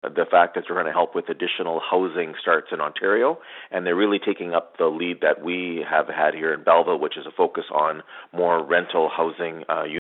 Belleville Mayor Mitch Panciuk